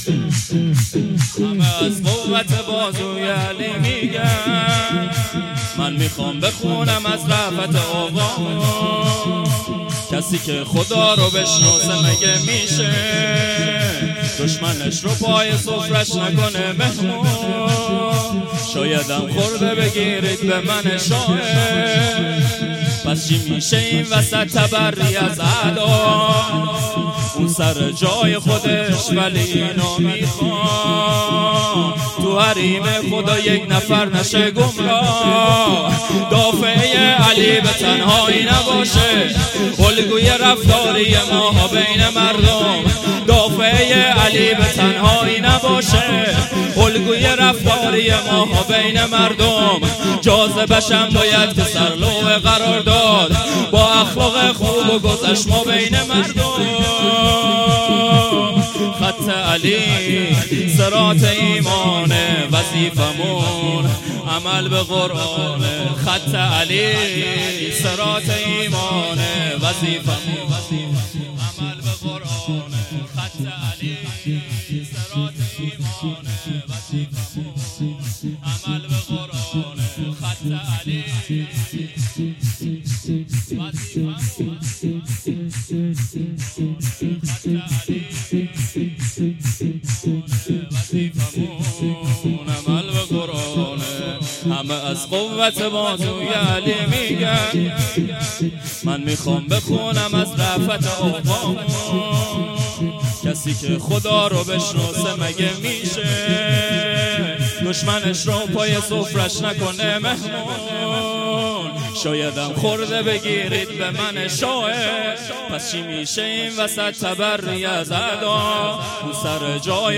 شور امیر المومنین(ع)